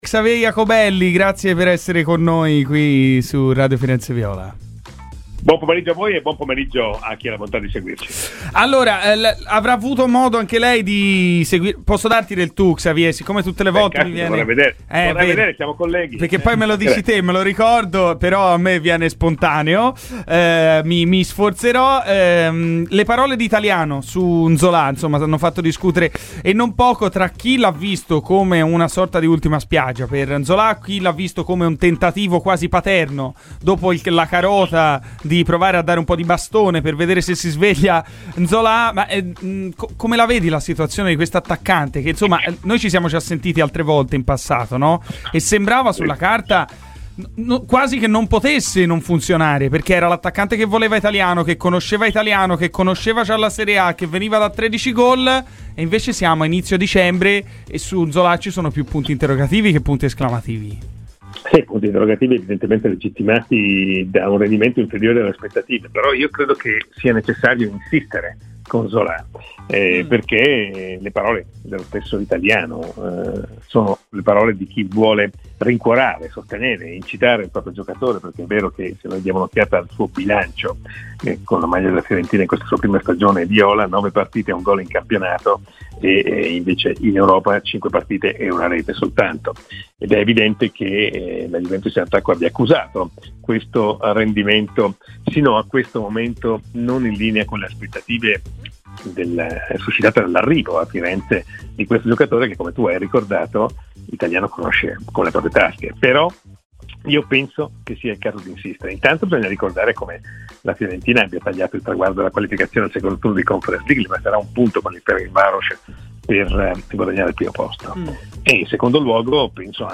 Per l'intervista completa, ascolta il podcast